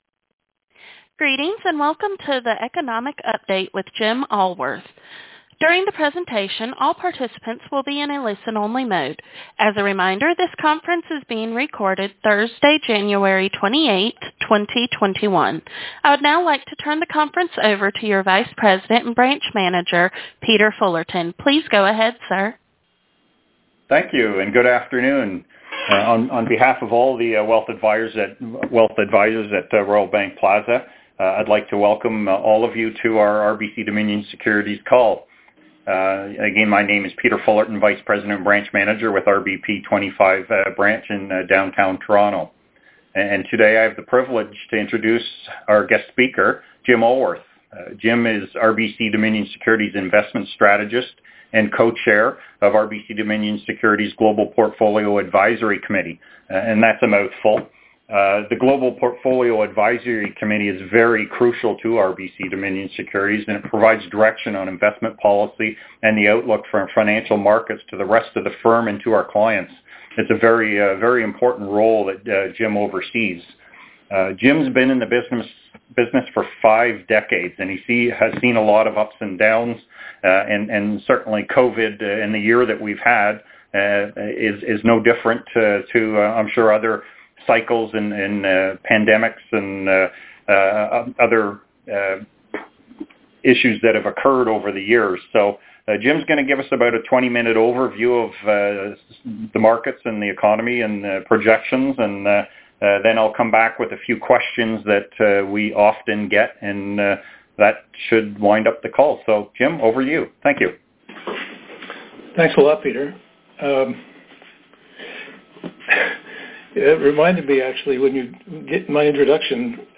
Our branch call